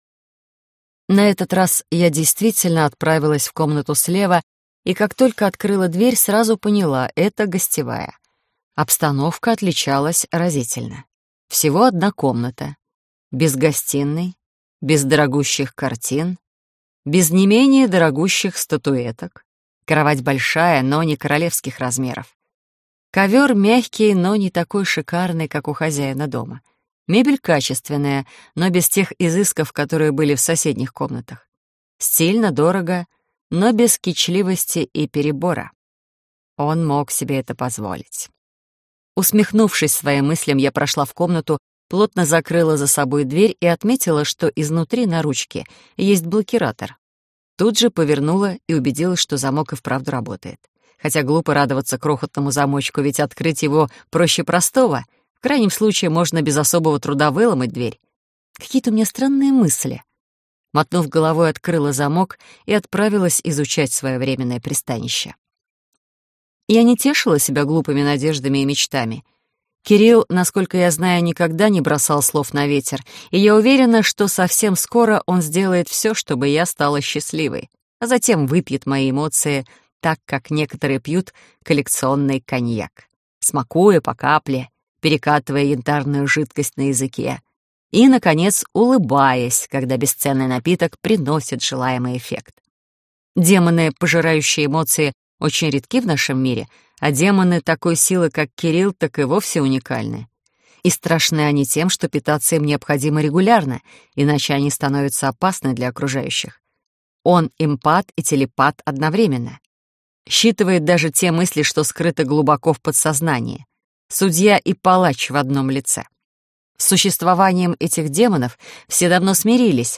Аудиокнига Моё смертельное счастье | Библиотека аудиокниг